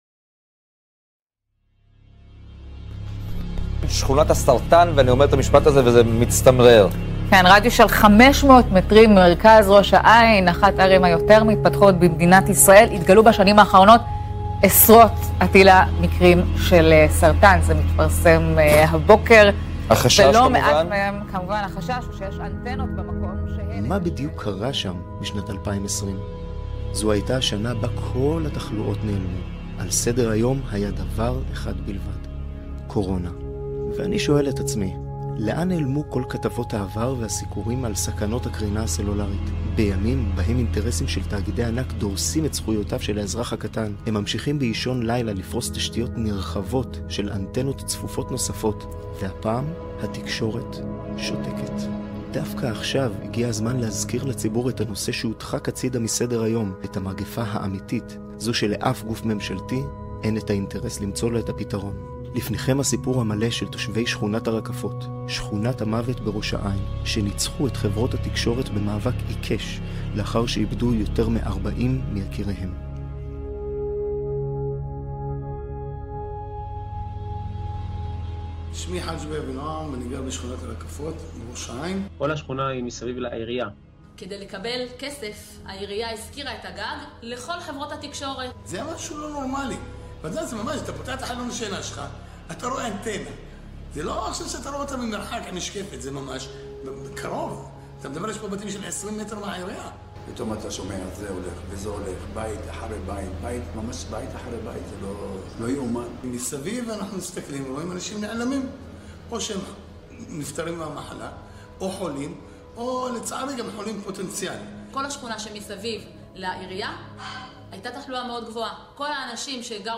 צילום עדויות התושבים והפקת הסרט